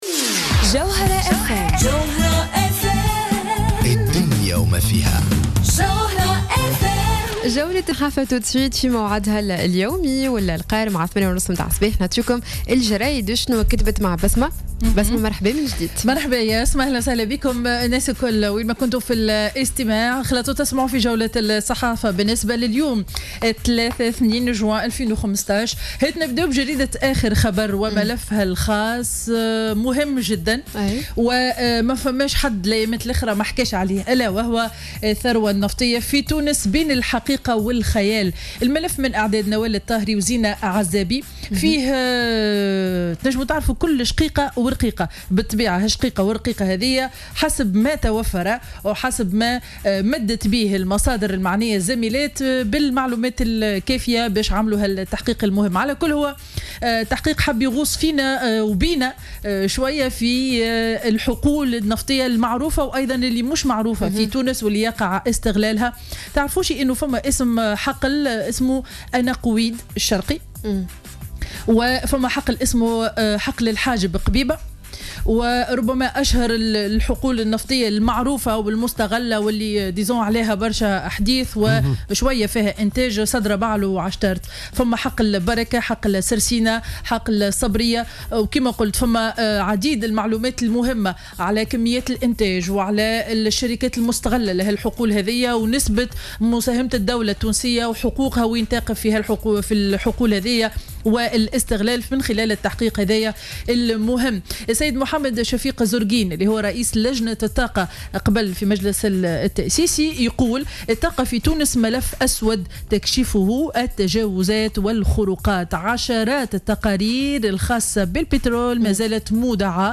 Revue de presse du 02 Juin 2015